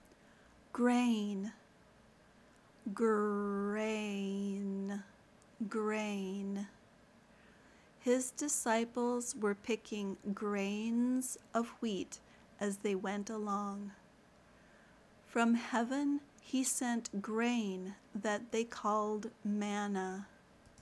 /ɡreɪn/ (noun)